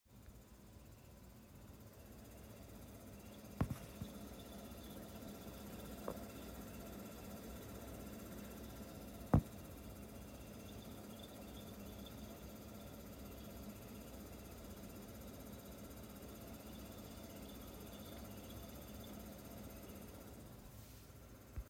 Mijn nieuwe koelkast maakt echt een subtiel maar KUT geluid.